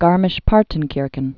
(gärmĭsh-pärtn-kîrkən, -ən)